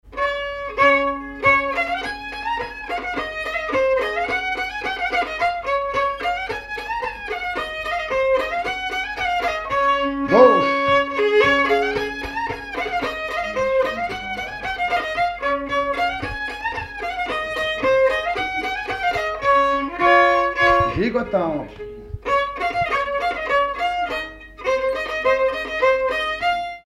danse : quadrille
circonstance : bal, dancerie
Pièce musicale inédite